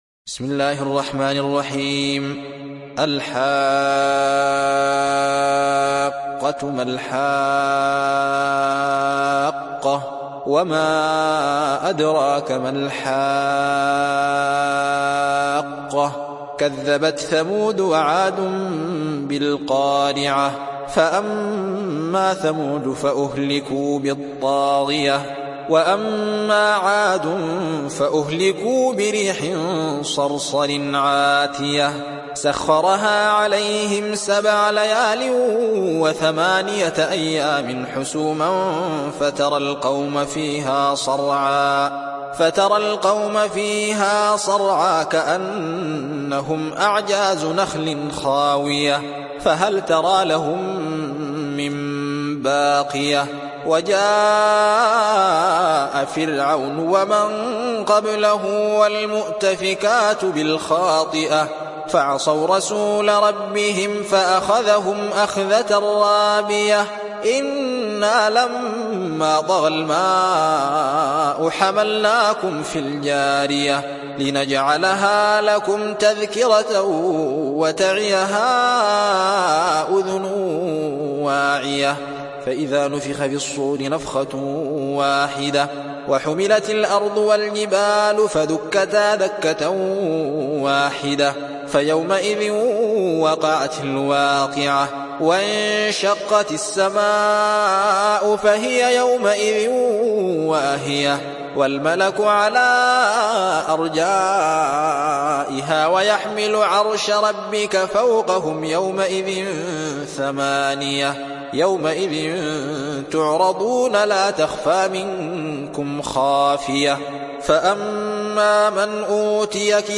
تحميل سورة الحاقة mp3 بصوت الزين محمد أحمد برواية حفص عن عاصم, تحميل استماع القرآن الكريم على الجوال mp3 كاملا بروابط مباشرة وسريعة